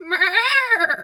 sheep_2_baa_10.wav